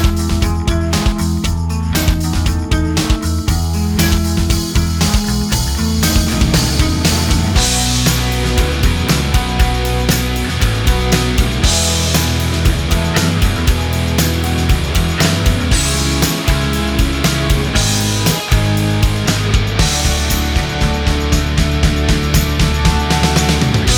Minus Main Guitars Indie / Alternative 4:20 Buy £1.50